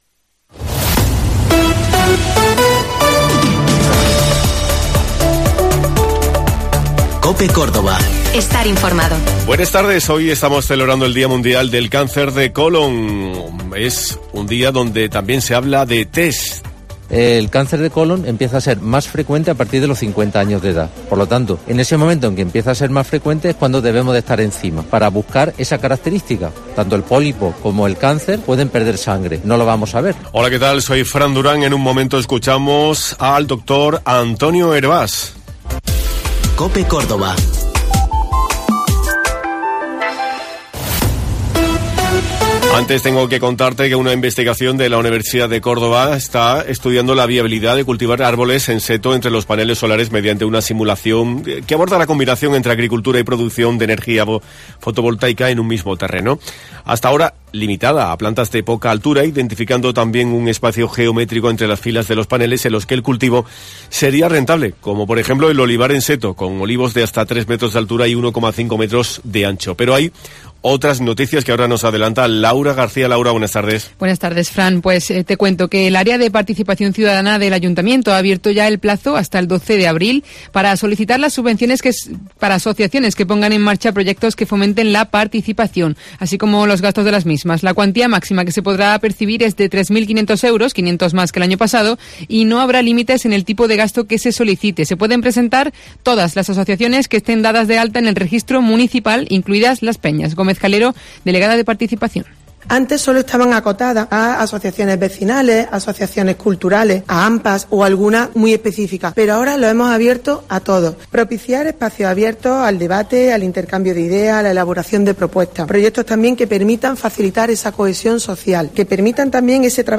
Hemos hablado con el doctor